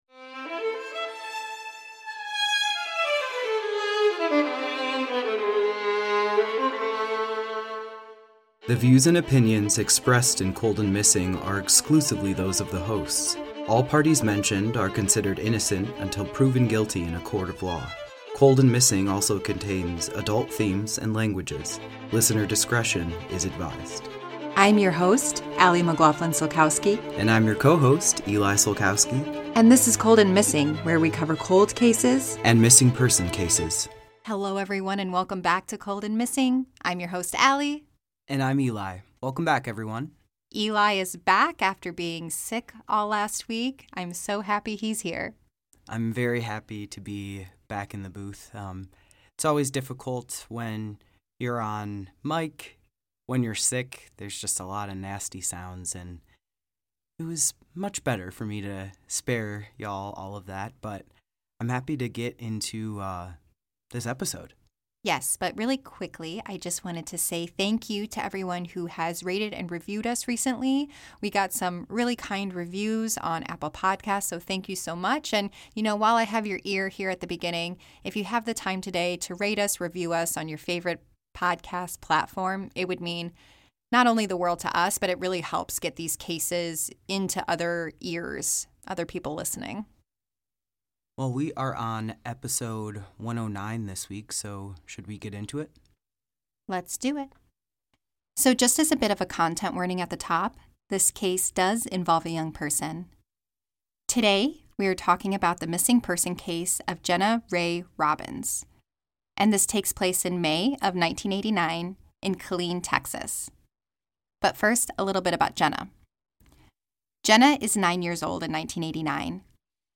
a husband and wife duo- will bring you either a Cold Case or a unresolved missing person.